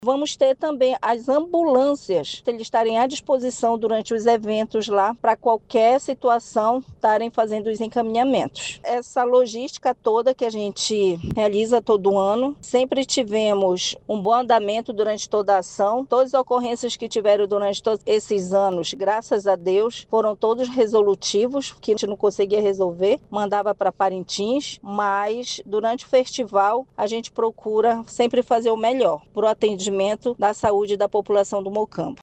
SONORA-1-ESTRUTURA-SAUDE-MOCAMBO.mp3